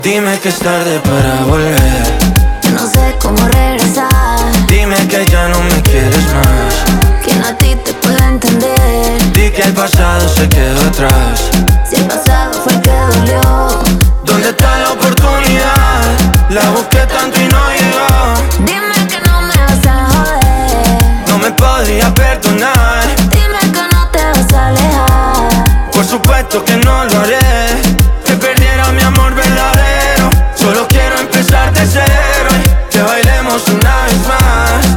Pop Latino Latin